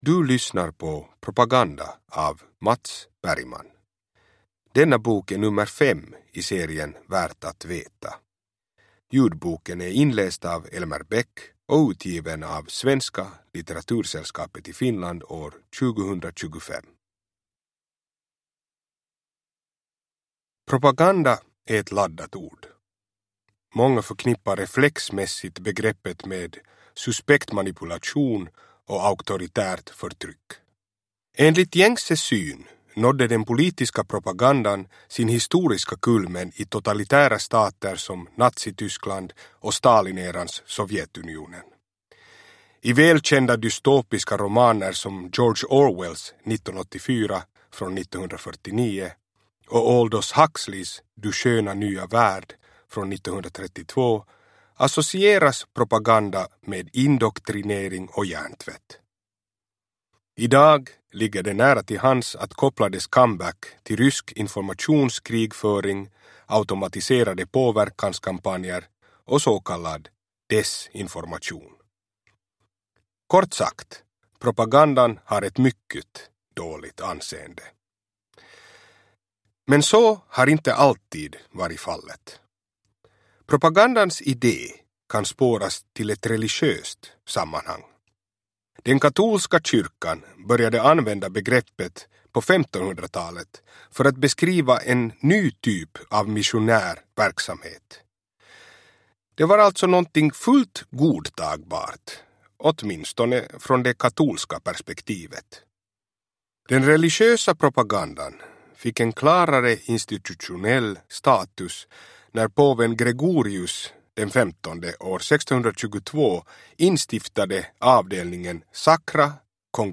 Propaganda – Ljudbok